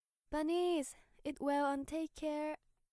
ハートの部分を押すと、メンバーの音声メッセージが流れます♪（ボイスサンプルはこちら） 特別感が味わえるアイテムです。